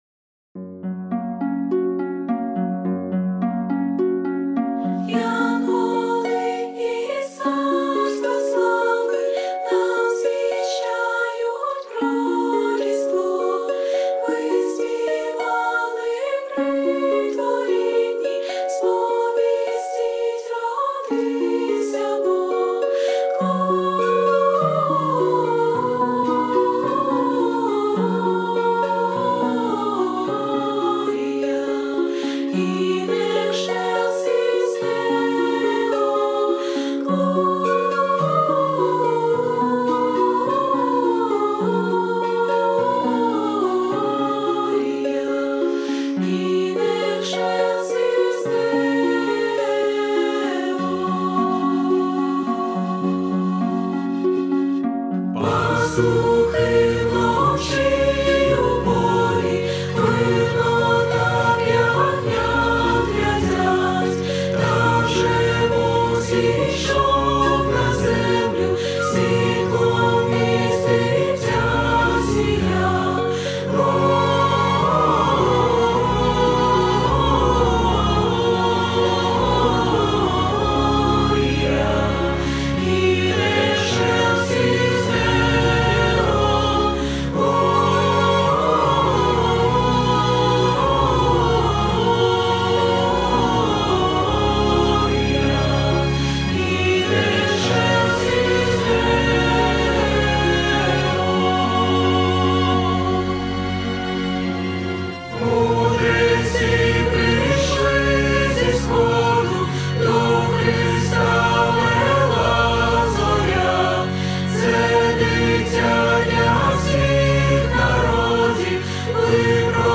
82 просмотра 110 прослушиваний 5 скачиваний BPM: 105